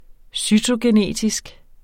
Udtale [ ˈsytogeˌneˀtisg ]